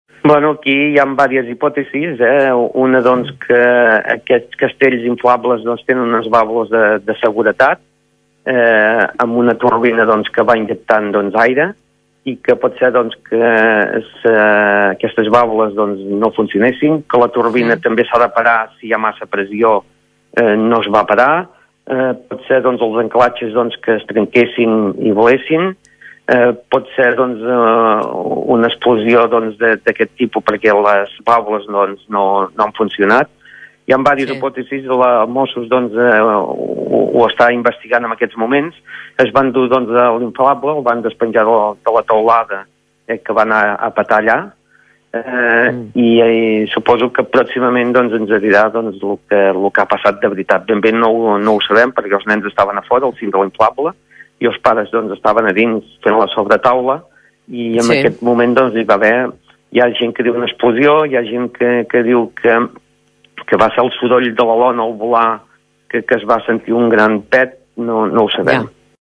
L’alcalde ha explicat que els mossos investiguen les causes del sinistre i que hi ha diverses hipòtesis obertes.